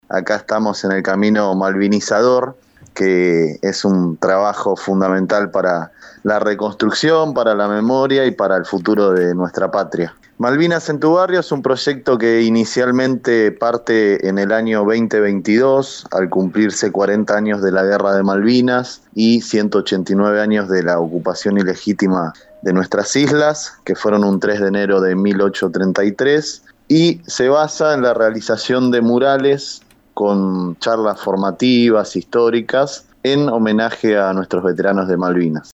conversó con FM Ilusiones